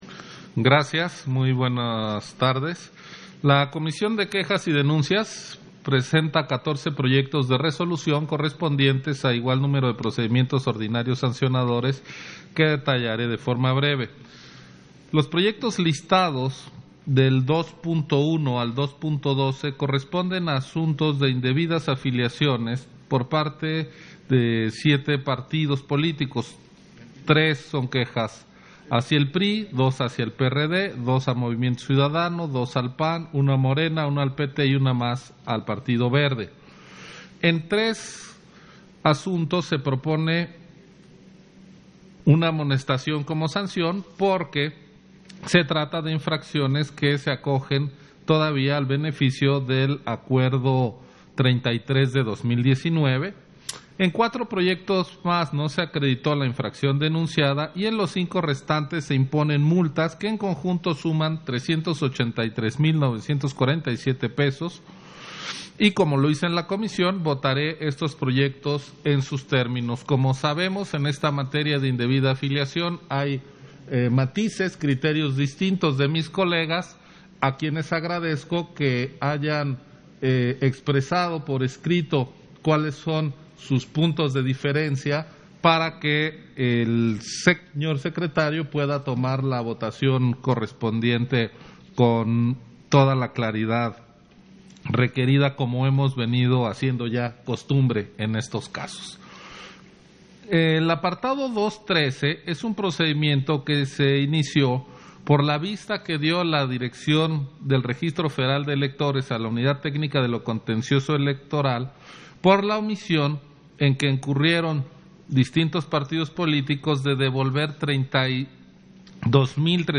140721_AUDIO_INTERVENCIÓN-CONSEJERO-MURAYAMA-PUNTO-2-SESIÓN-EXT. - Central Electoral